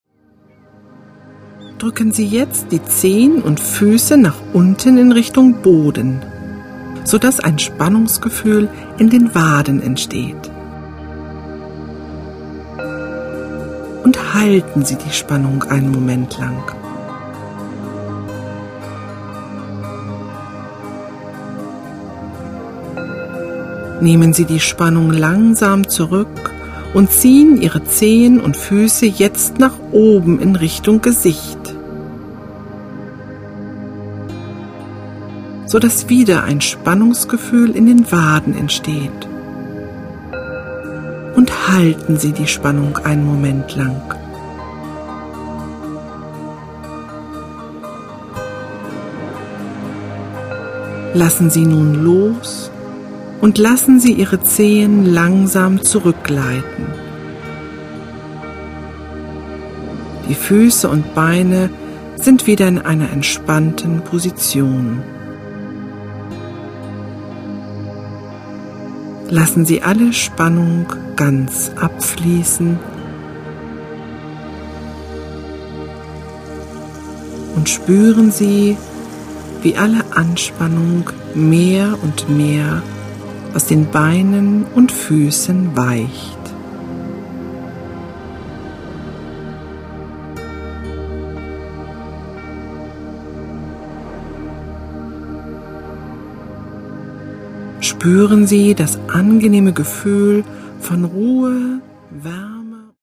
Musik: N.N.